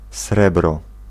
Ääntäminen
US : IPA : /ˈɑɹ.dʒɛnt/ RP : IPA : /ˈɑː.dʒɛnt/